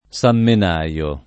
[ S am men #L o ]